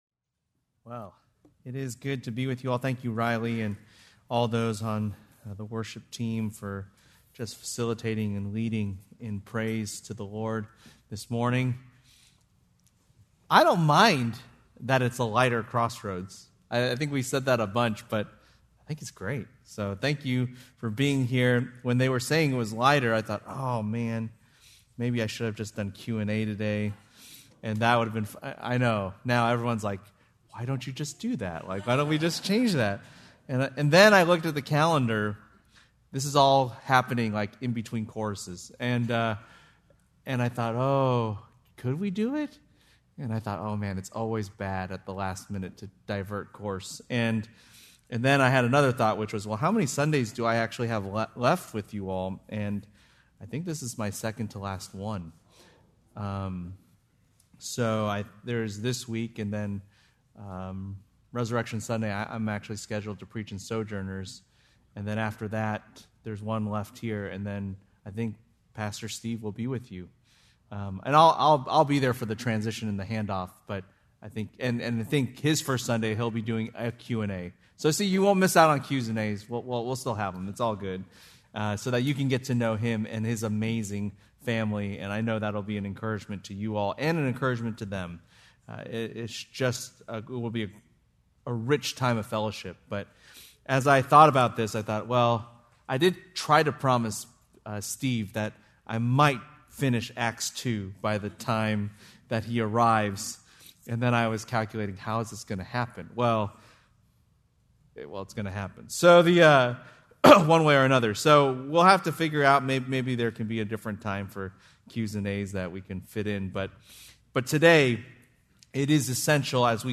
March 29, 2026 - Sermon